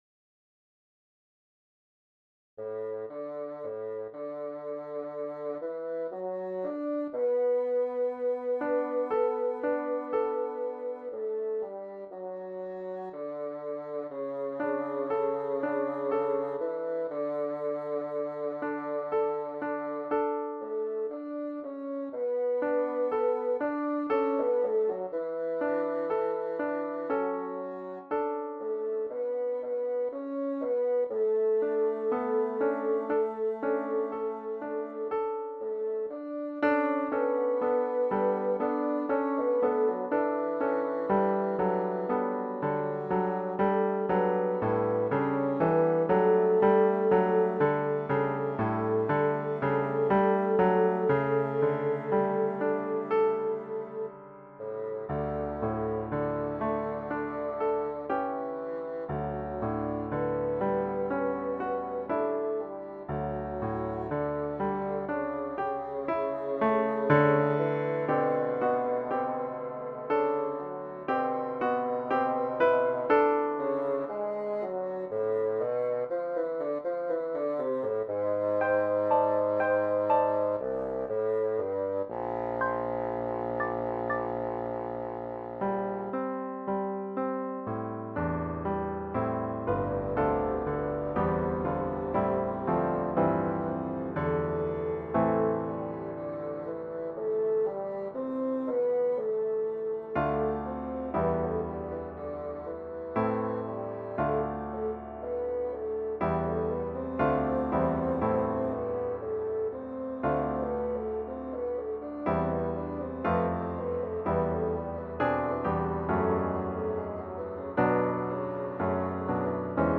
PettyHarbourBassoon.mp3